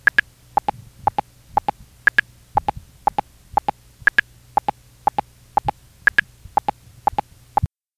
You should get a new track with the ticks slightly distorted from the round trip, but exactly one latency off.
This sound clip is a more normal close but no cigar.